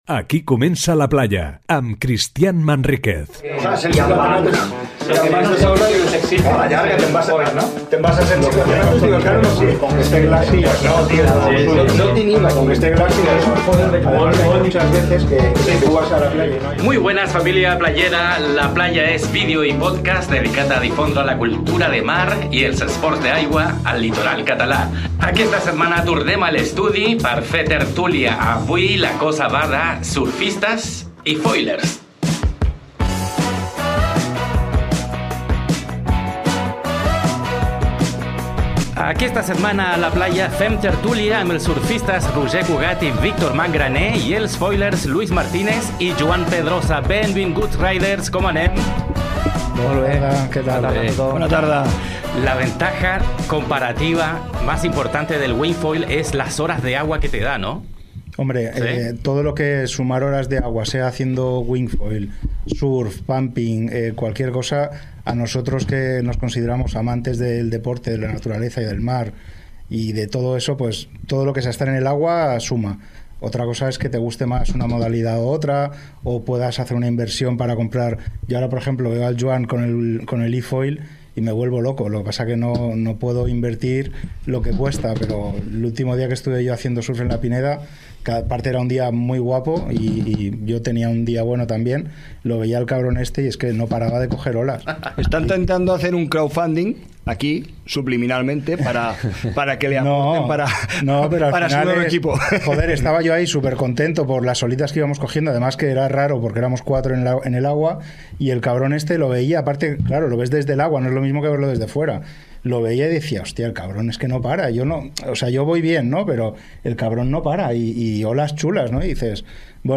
Surfistes i foilers a la taula parlant de surfing i foil a la costa daurada